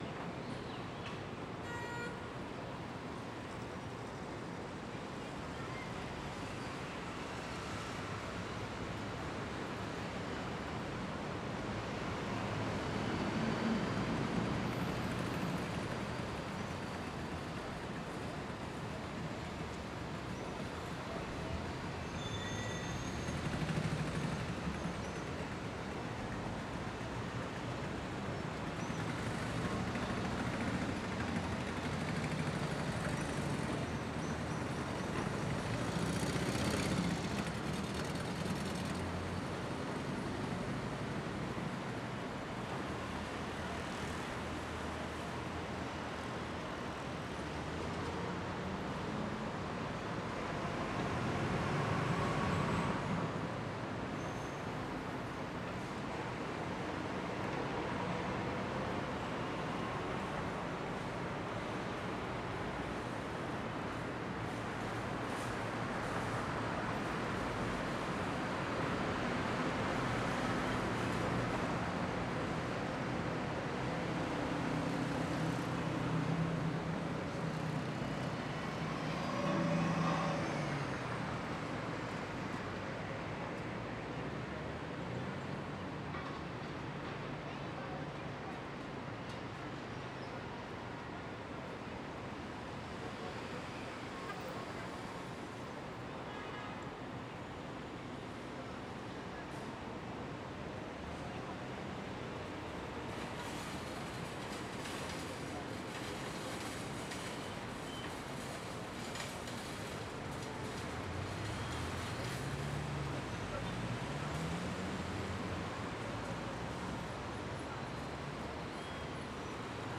Sci-Fi Sounds / Hum and Ambience / City Loop 3.wav
City Loop 3.wav